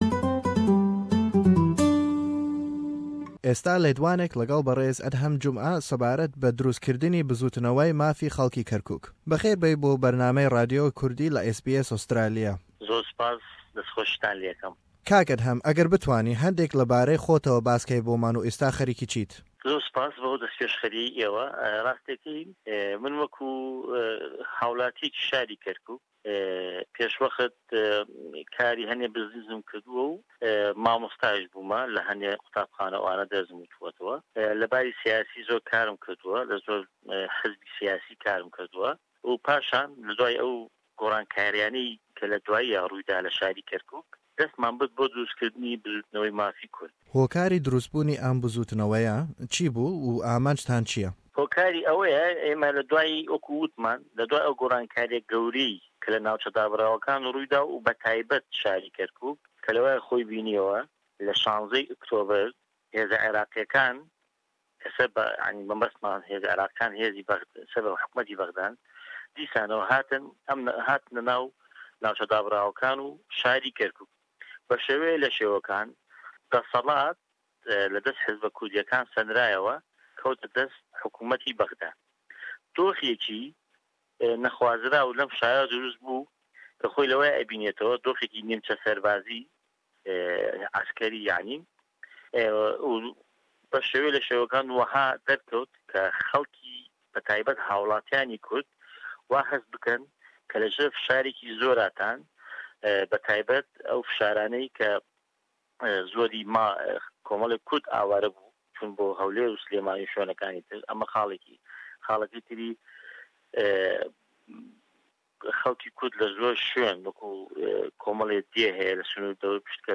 Lem lêdwane basî ho û amancyan dekat, zrûfî Kerkuk û nexşî Kurdî derewe.